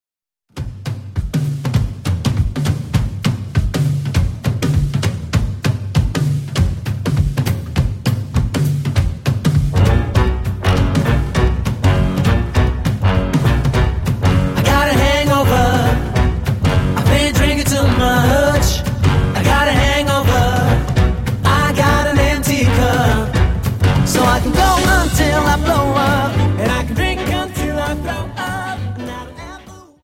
Dance: Quickstep 50